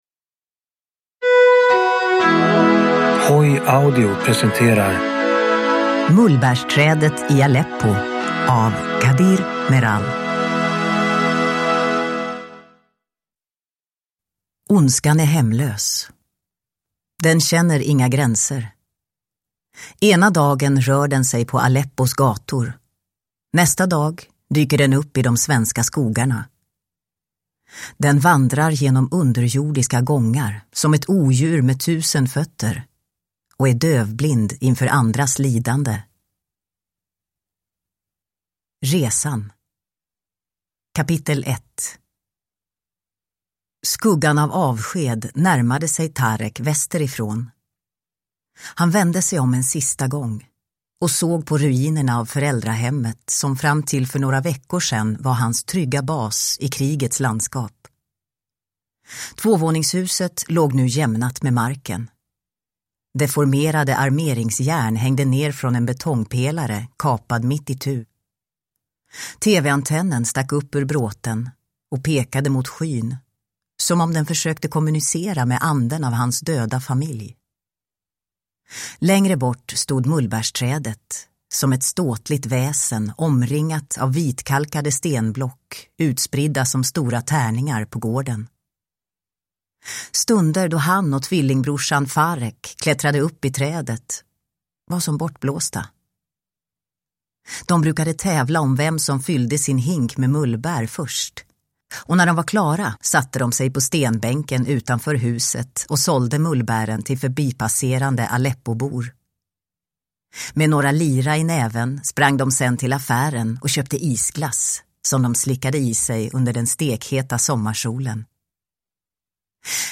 Mullbärsträdet i Aleppo (ljudbok) av Kadir Meral